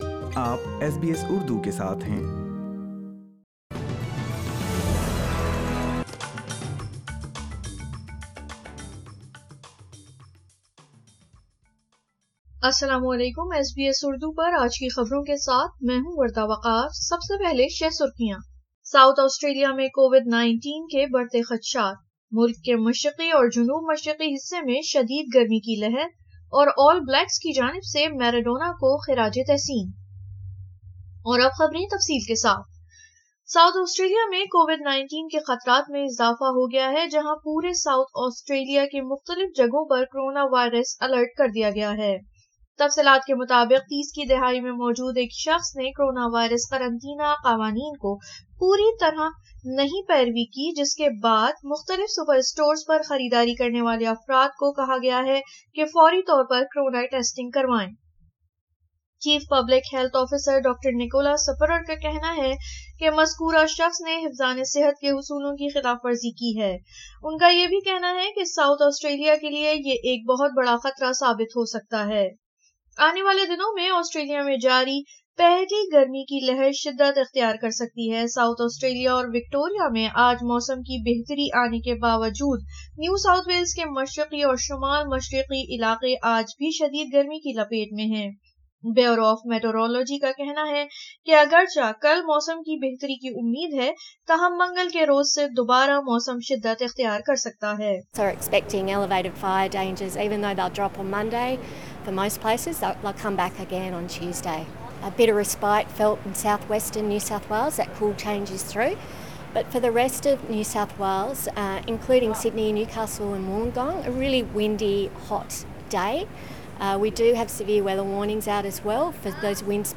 آسٹریلیا میں موسم شدت اختیار کر رہا ہے۔ ساوتھ آسٹریلیا میں کرونا کے بڑھتے خطرات۔ سنیئے اردو میں خبریں